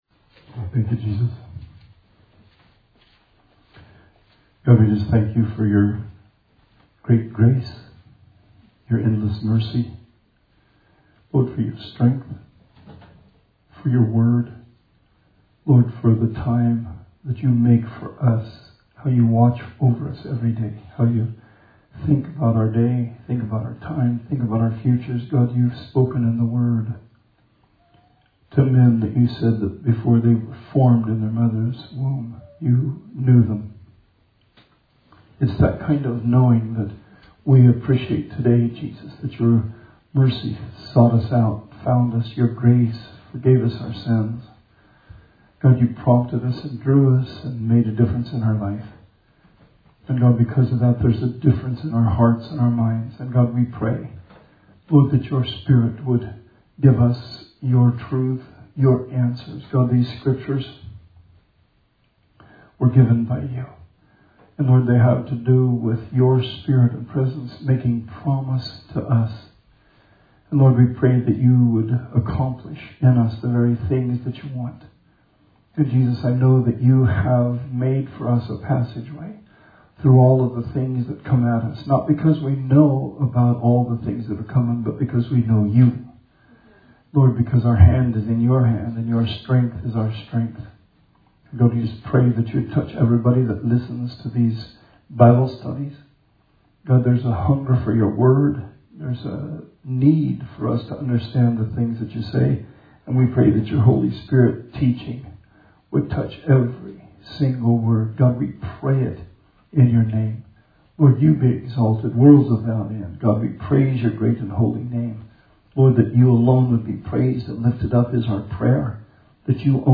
Bible Study 6/3/20